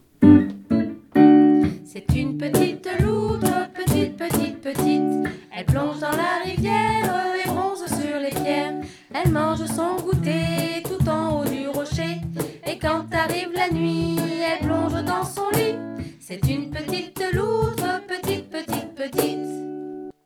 Des comptines pour les petits :
7 assistantes maternelles du RPE des Côteaux et du Plateau ont  collaboré pour mettre en musique cinq comptines qui ont été mises en musique avec des enseignants de l'école Intercommunale de musique et danse de Arche Agglo, et que vous pouvez retrouver ici.